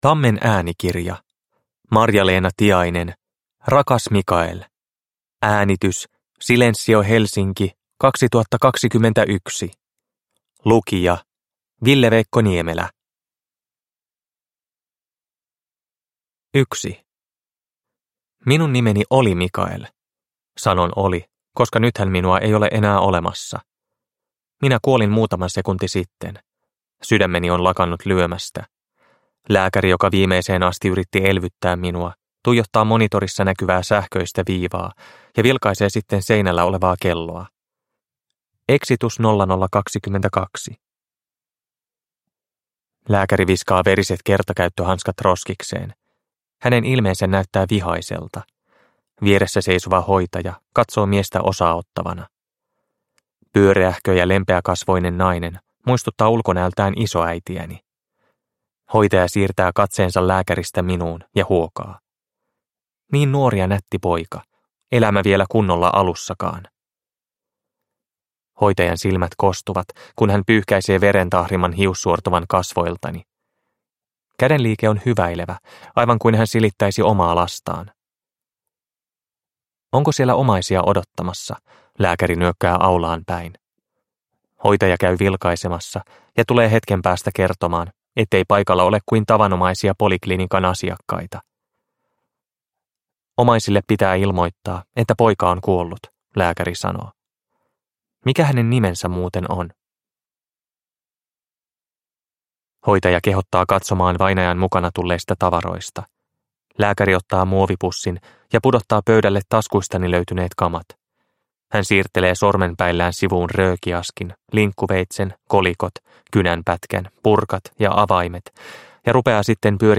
Rakas Mikael (ljudbok) av Marja-Leena Tiainen